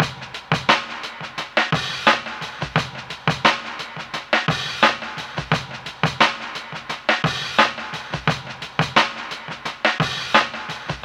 Drum Break.wav